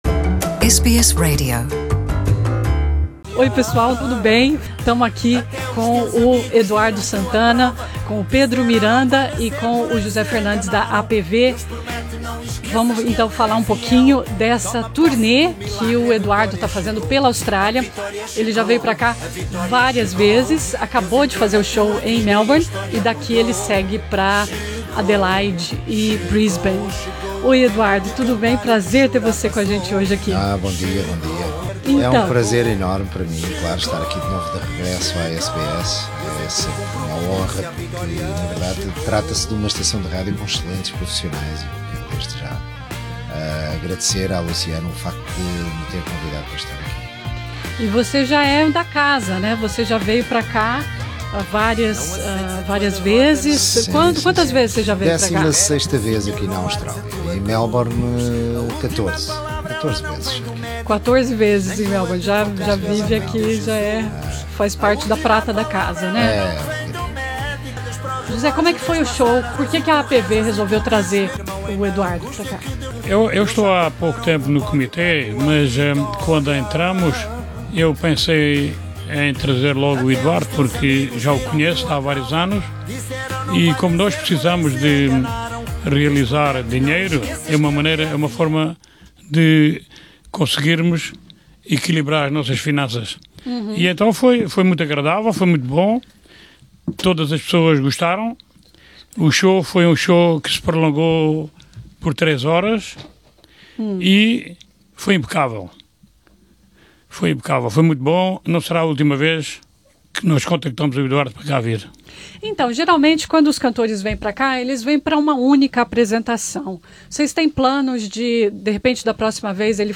Nessa entrevista à SBS em Português ele também alerta para a importância do associativismo como manutenção da cultura portuguesa na Austrália.